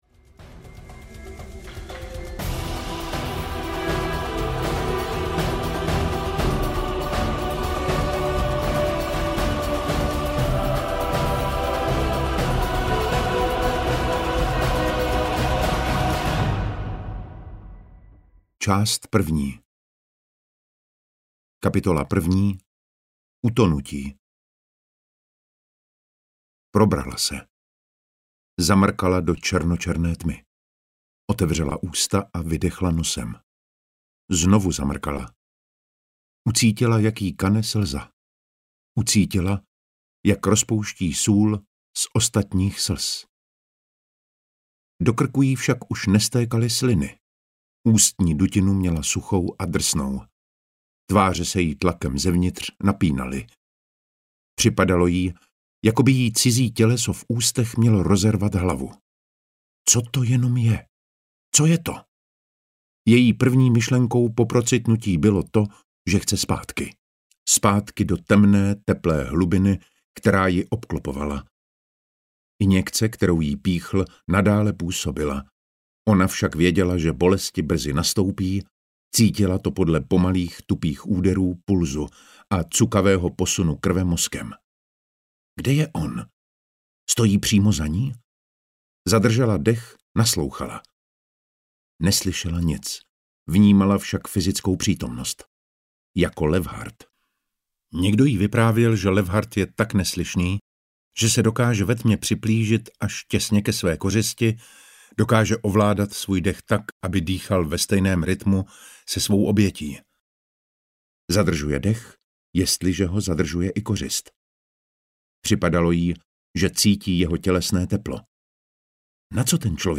Levhart audiokniha
Ukázka z knihy
• InterpretDavid Matásek